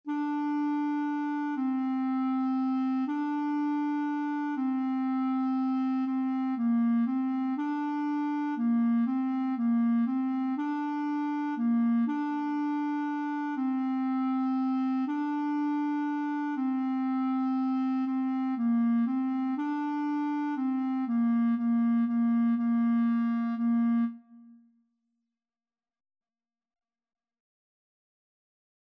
3/4 (View more 3/4 Music)
Bb4-D5
Clarinet  (View more Beginners Clarinet Music)
Classical (View more Classical Clarinet Music)